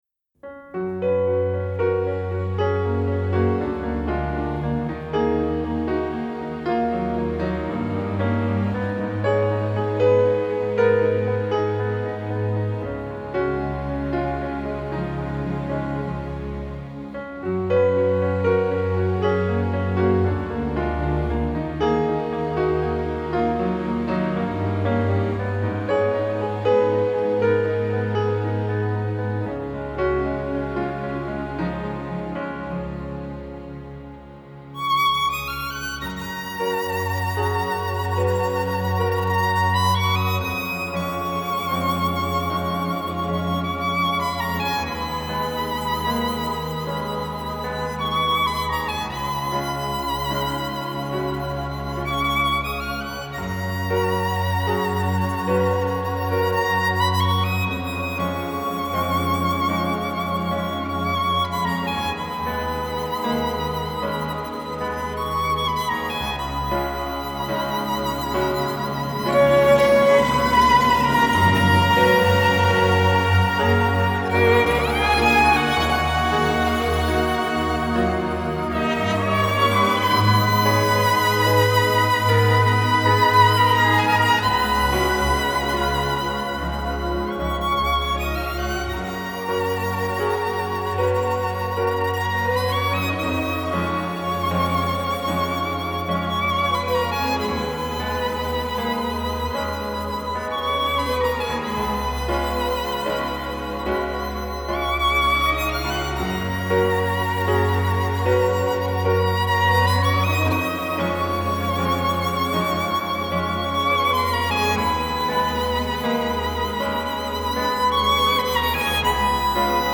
Genre: Pop, Instrumental, Easy Listening